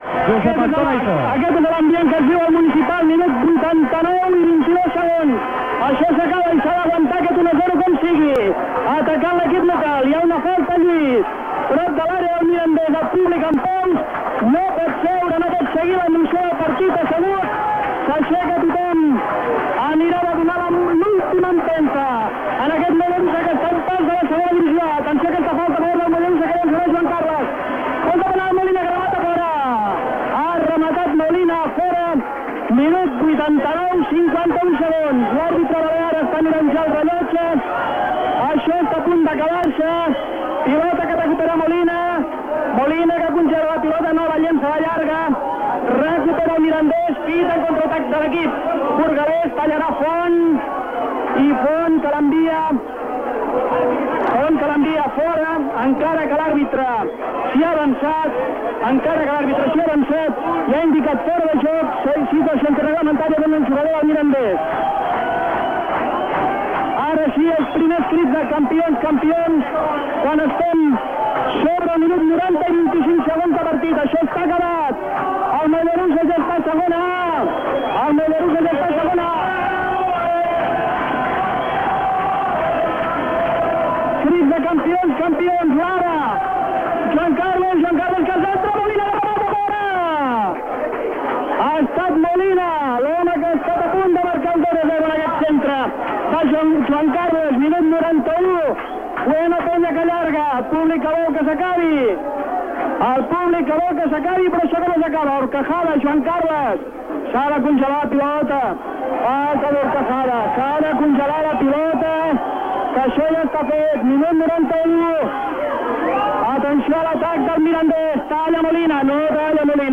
Transmissió del partit de futbol masculí entre el Mollerussa i el Mirandès.
Esportiu